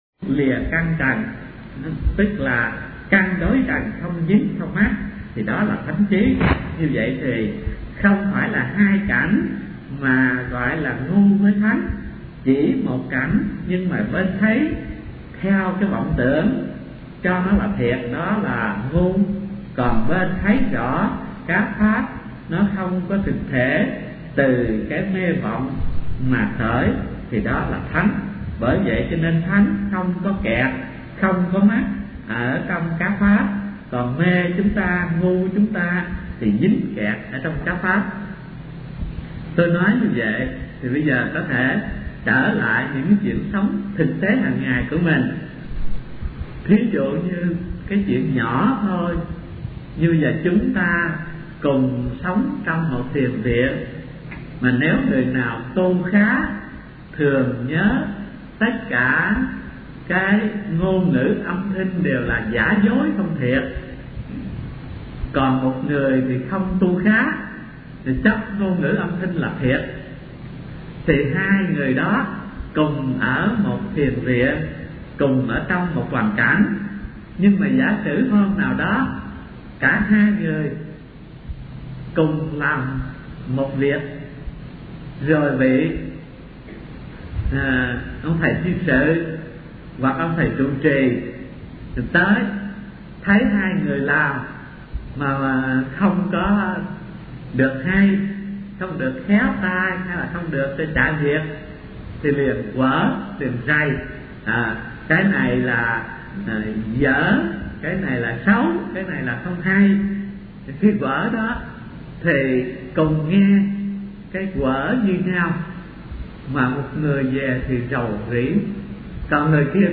Kinh Giảng Kinh Lăng Già - Thích Thanh Từ